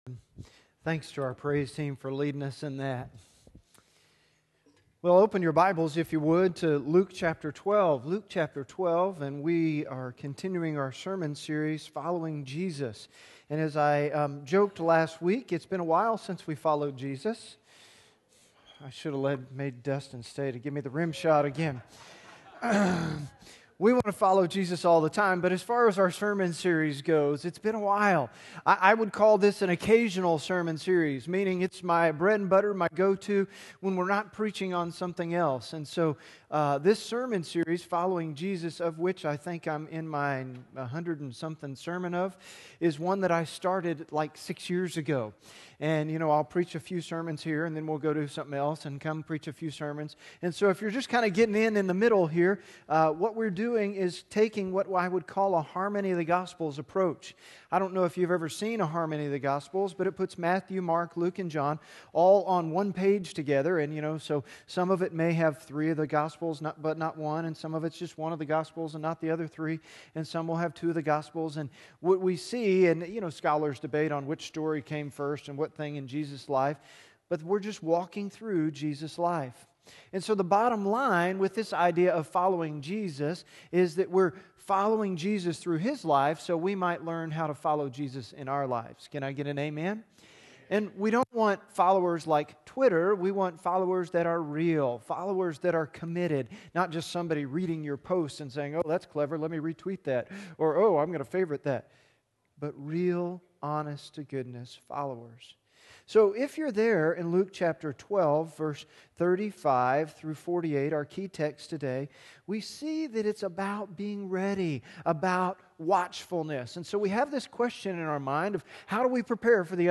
An archive of sermon recordings preached at Southview Baptist Church in Lincoln, NE.